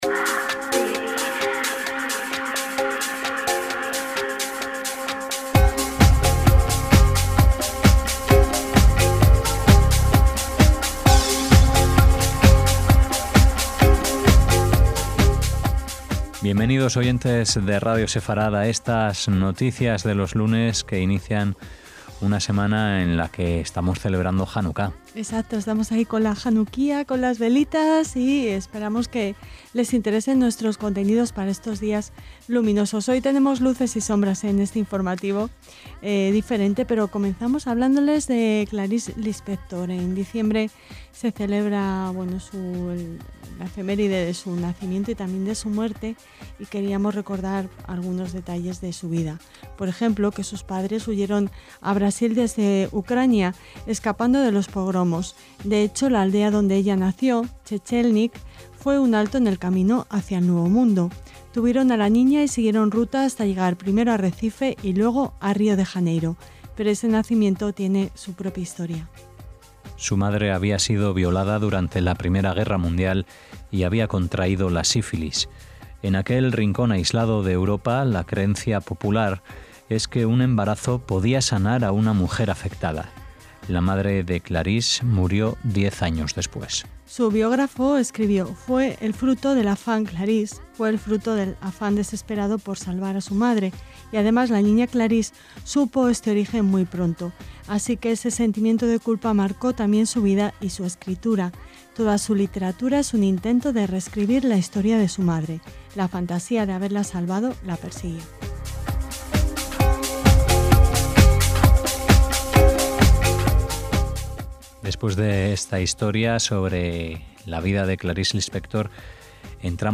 De Janucá es la música que acompaña nuestro paseo por el archivo histórico.